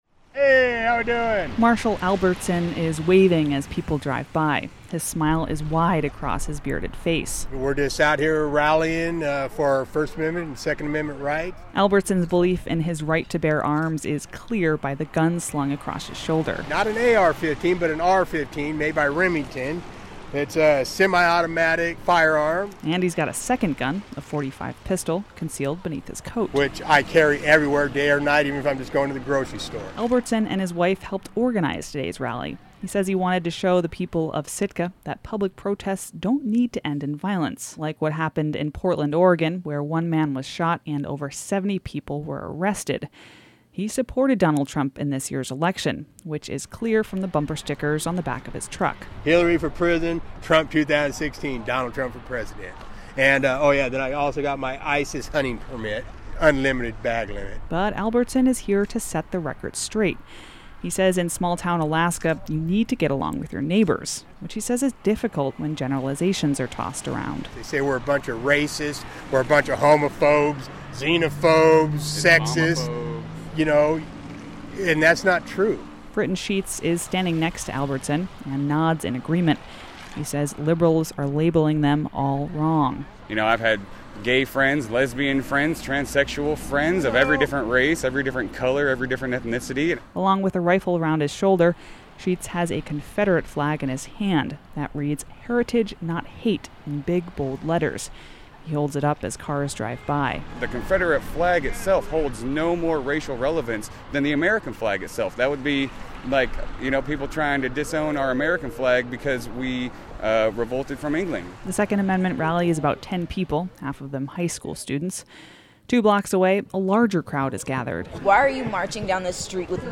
The circle of people begin singing “We Shall Overcome.”